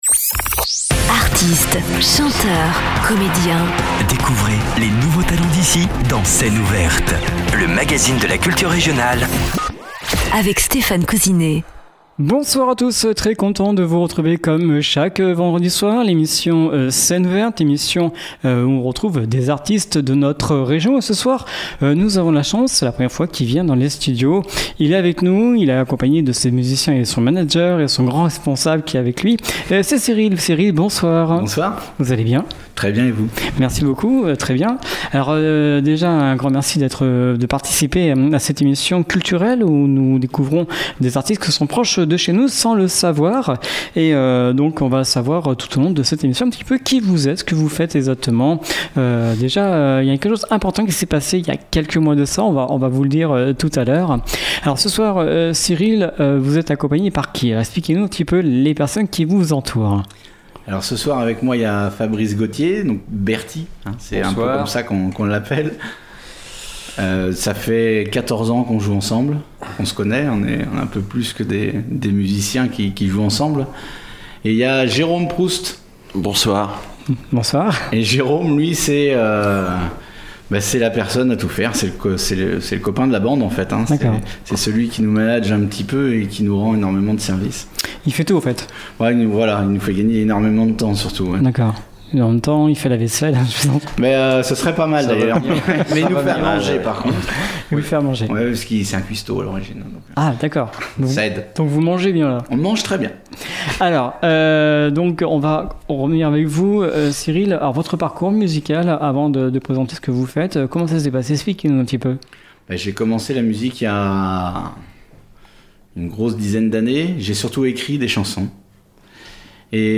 basse
guitare
batterie